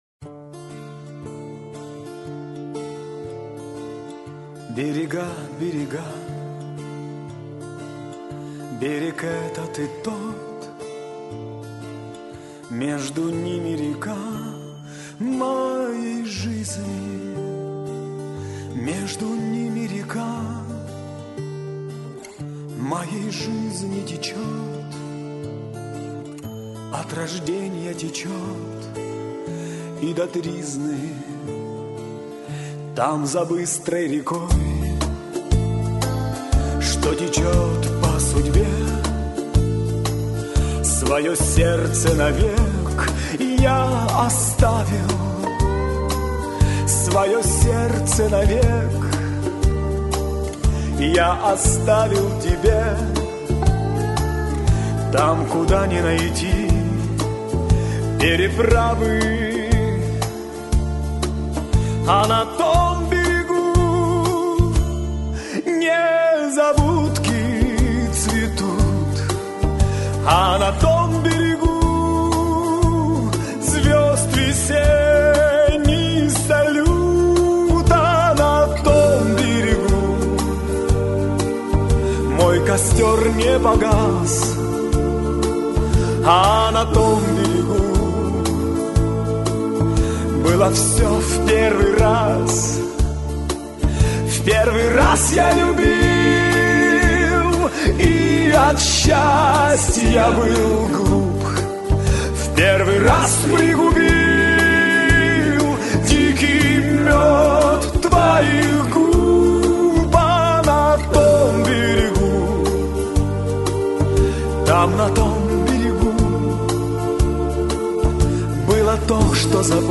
Спасибо за красоту и чувственную песню.